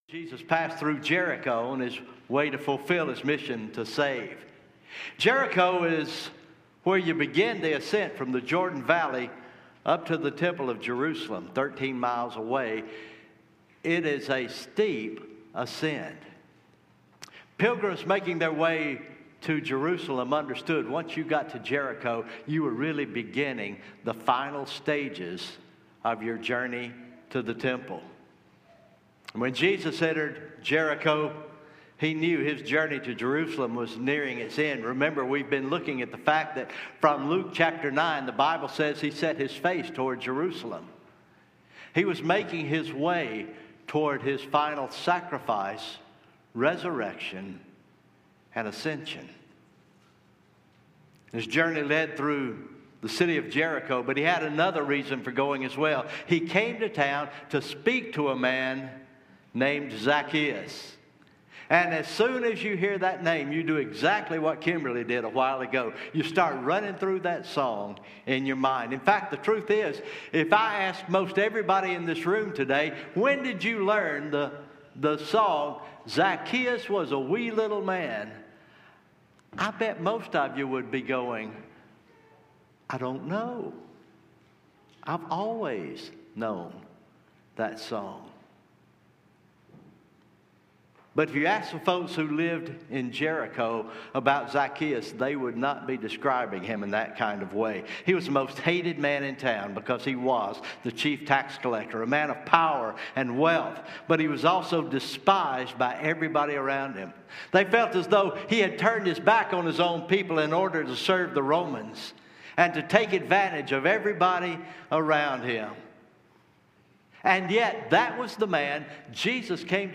Sermon Recordings
Morning Worship